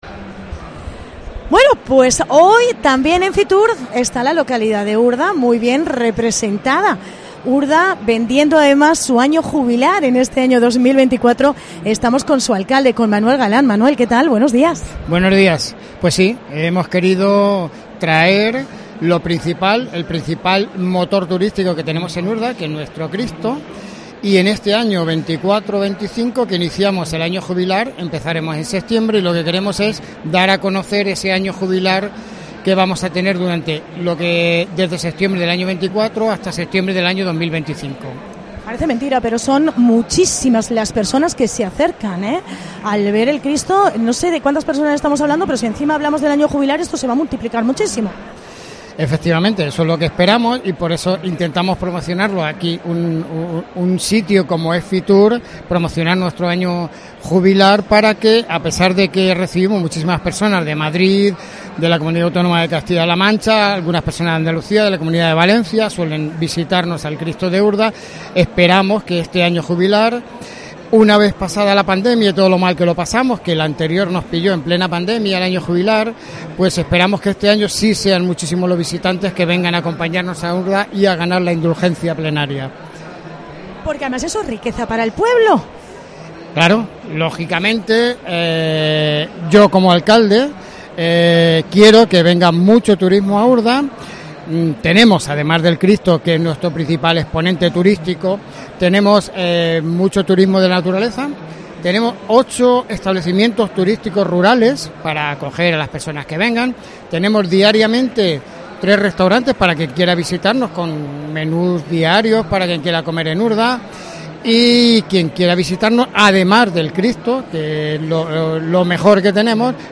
FITUR | Entrevista a Manuel Galán, alcalde de Urda